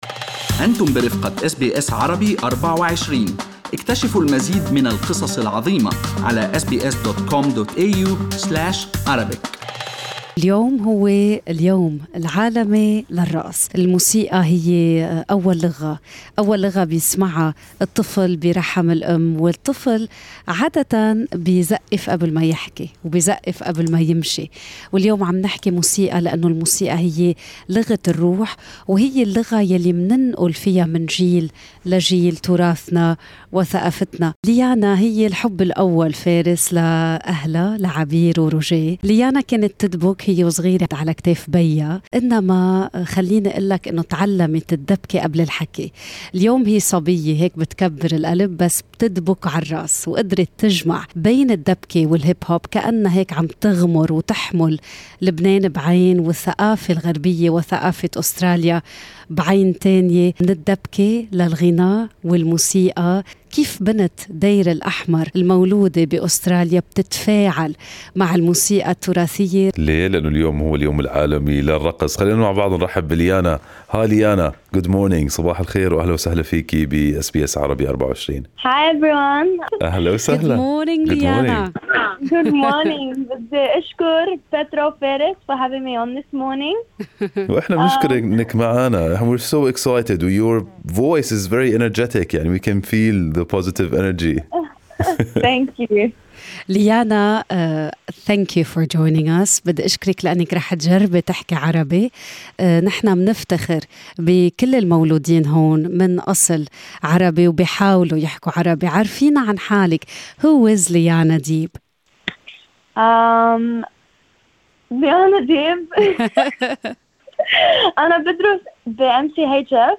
Lebanese Australian